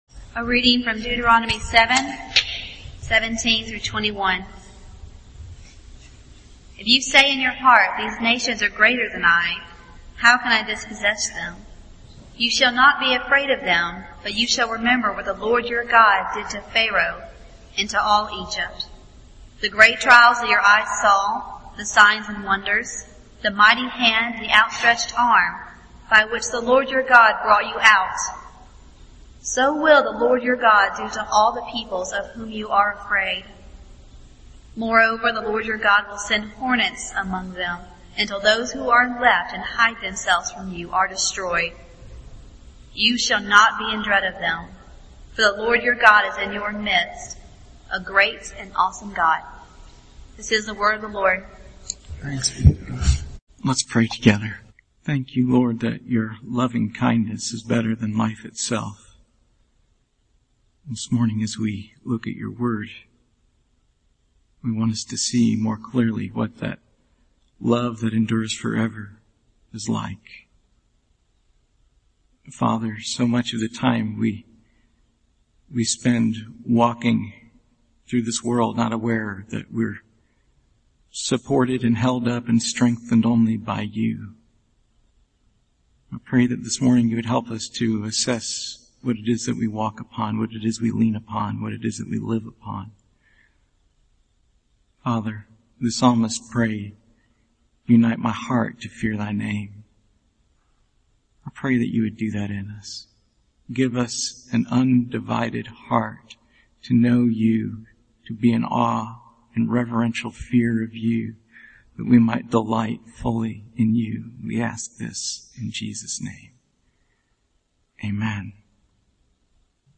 Passage: Deuteronomy 7:1-26 Service Type: Sunday Morning